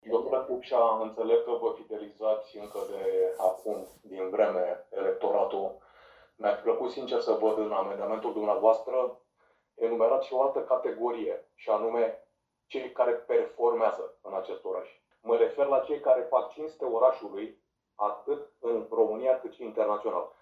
O reacție la declarațiile aleșilor de la AUR a avut și consilierul local de la Forța Dreptei, Alexandru Sorin Bădoiu.